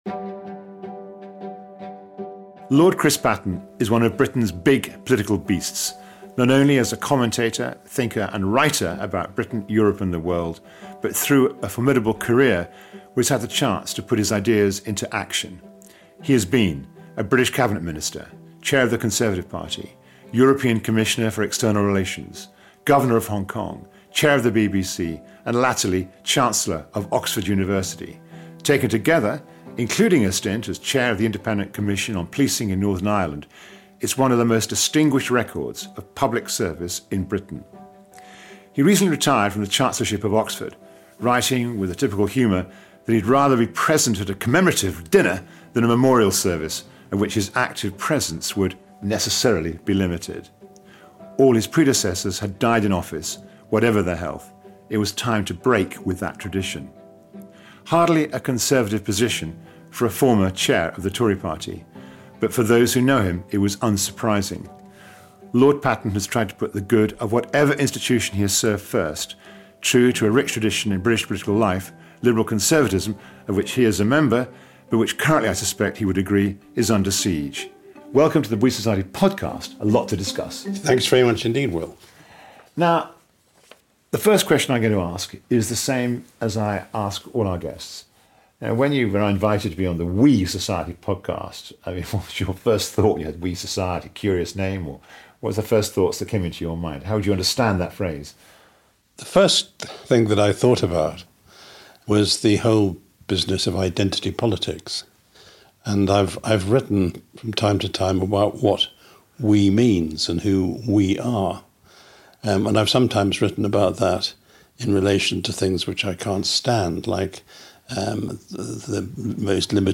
Hosted by journalist and Academy President Will Hutton, we interview some of Britain’s top social scientists and public figures from across the globe to explore their evidence-led solutions to society’s most pressing problems.